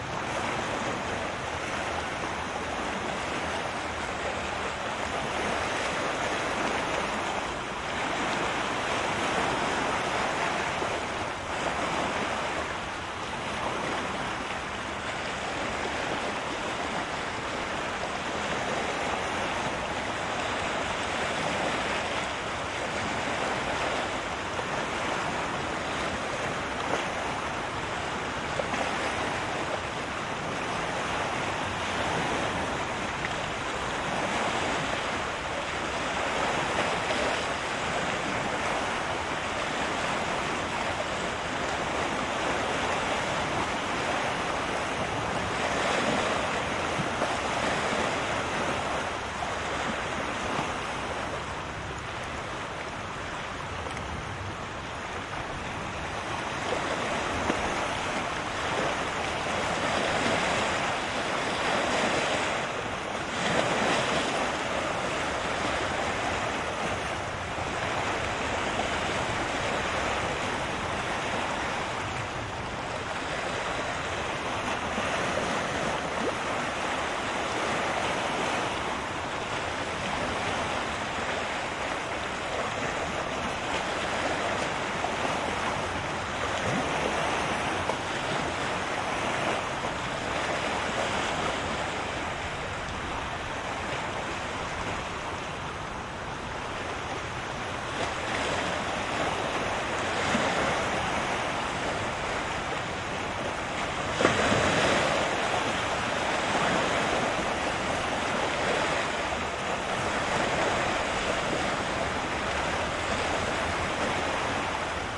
湖中的浪花02
Tag: 沙滩 海岸 海浪 沿海 海洋 海滨